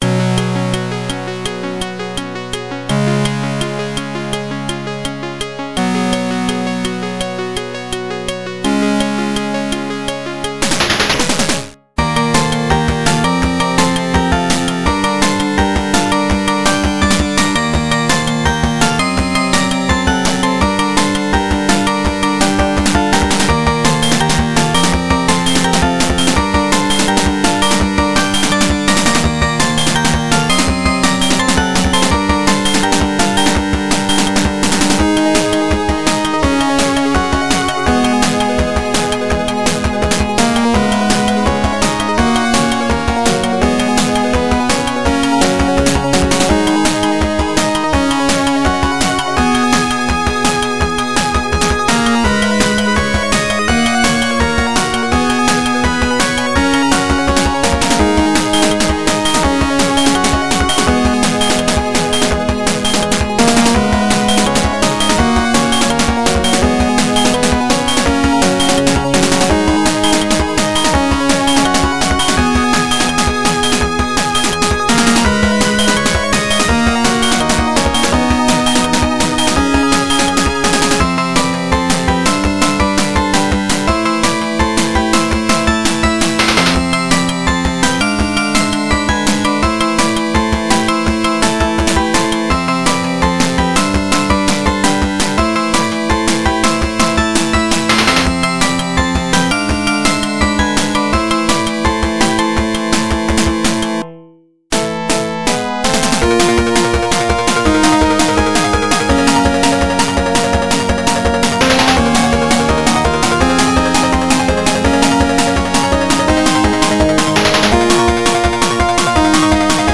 原游戏FM26K版，由PMDPlay导出。